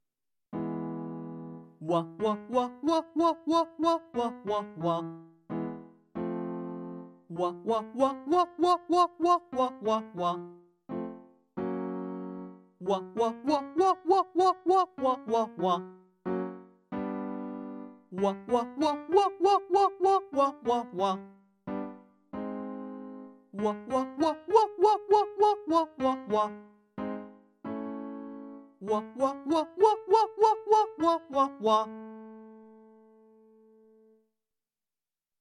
• 地声からスタートして裏声まで到達する音階で練習する
• 喉頭の位置を少し上げて「ゥワ」と発音する
• 高くなってもなるべく地声の要素を手放さない
音量注意！